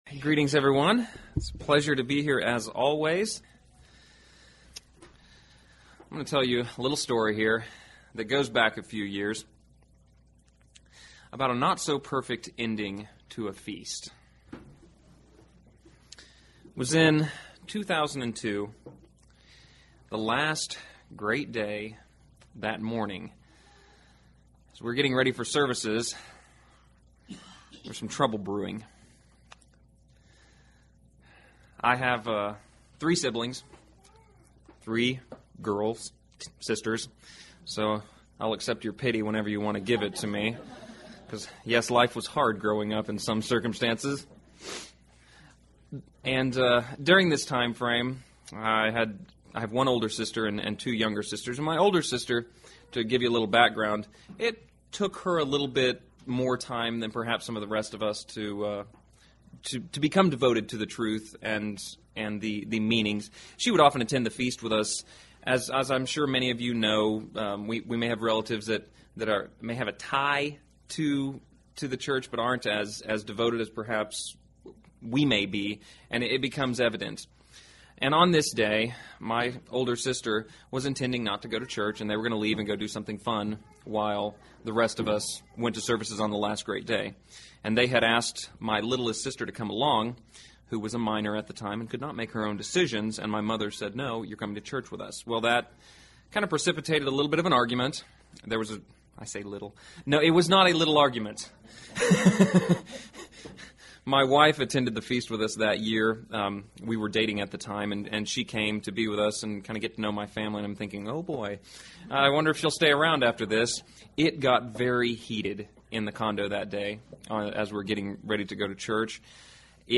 Given in Lawton, OK
UCG Sermon Studying the bible?